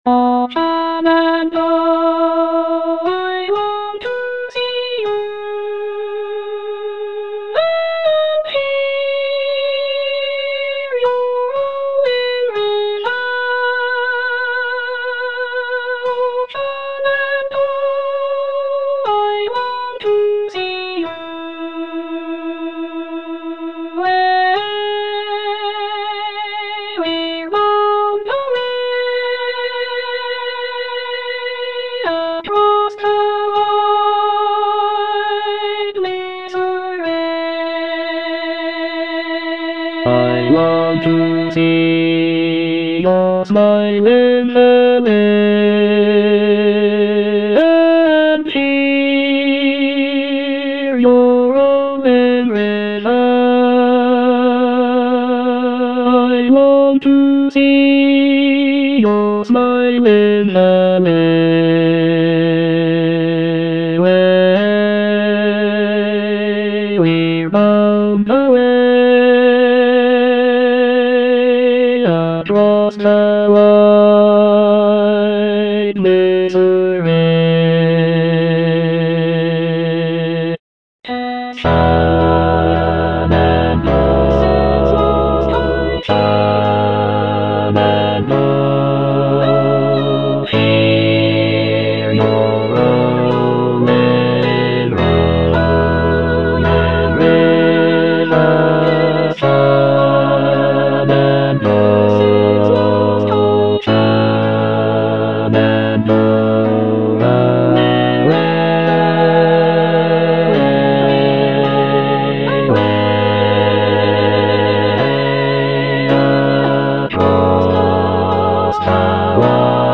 Bass II (Emphasised voice and other voices)